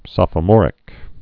(sŏfə-môrĭk, -mŏr-)